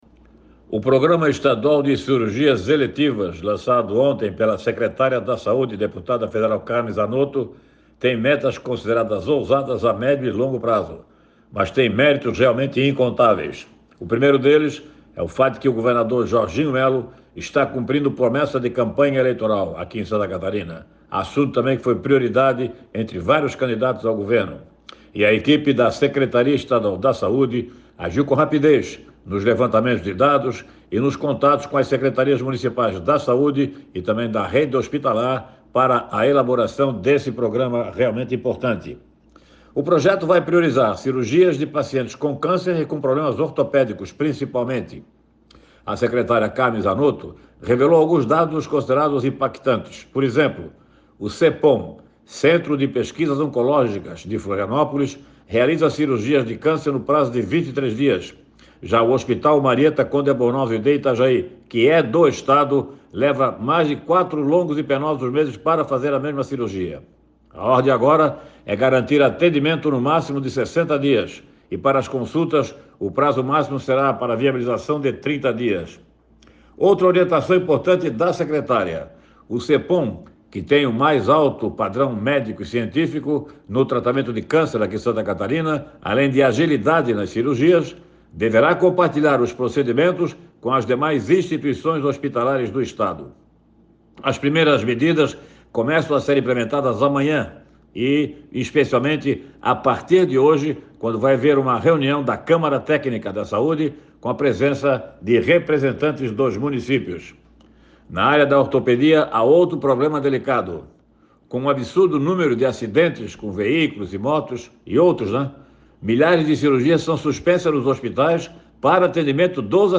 No comentário desta terça-feira, o jornalista ressalta que o lançamento do programa visa reduzir o tempo de espera para procedimentos cirúrgicos e consultas no Estado